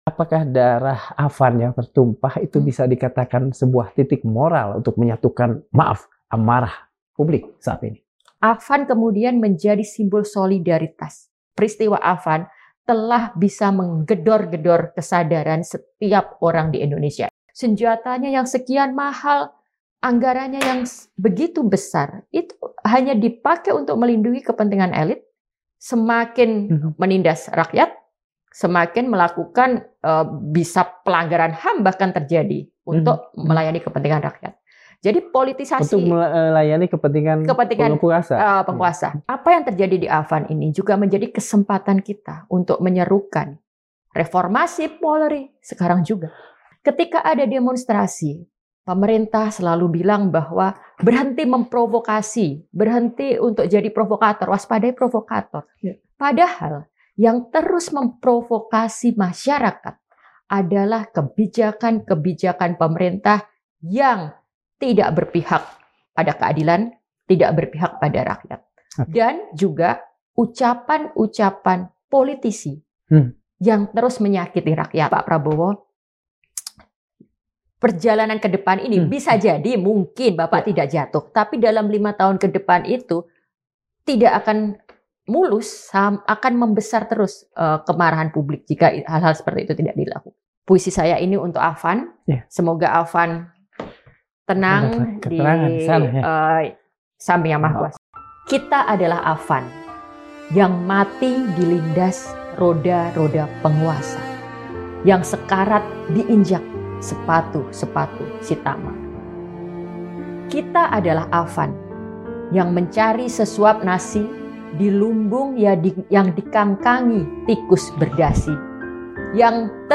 Narasumber: Dr. Okky Madasari - Pengamat Sosial Politik